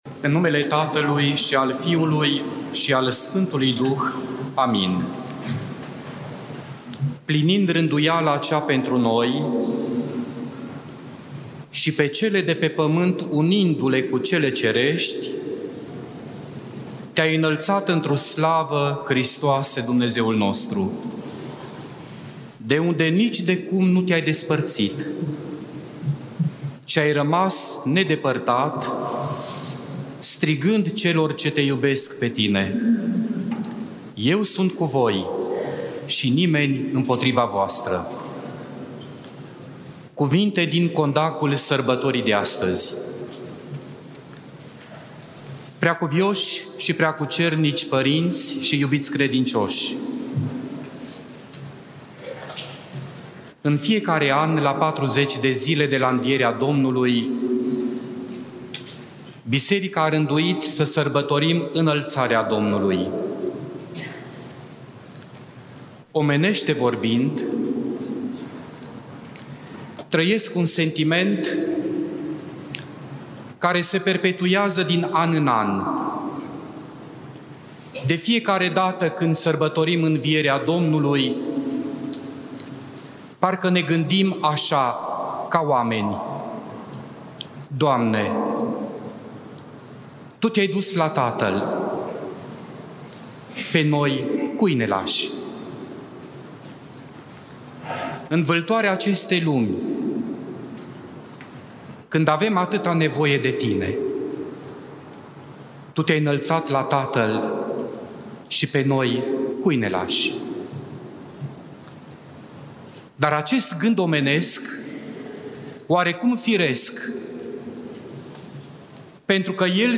Cuvinte de învățătură Predică la Praznicul Înălțării Domnului